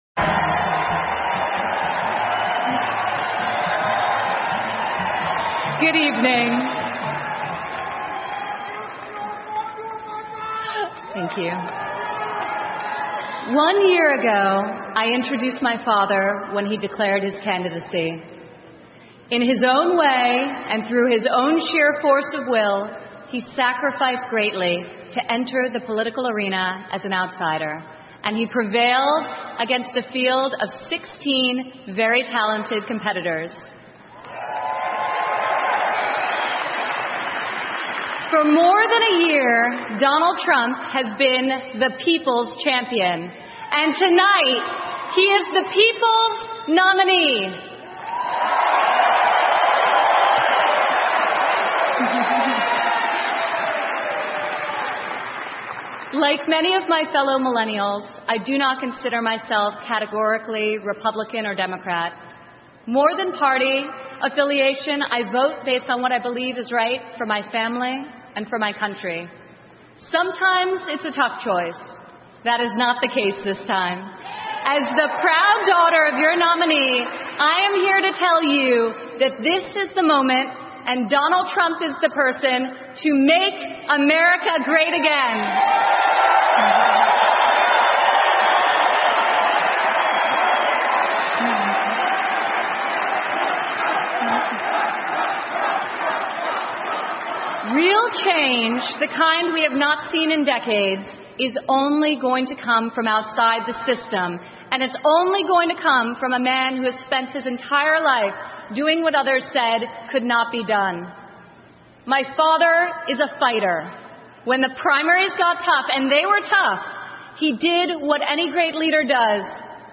美国总统大选演讲 伊万卡·特朗普为唐纳德·特朗普的助选演讲(1) 听力文件下载—在线英语听力室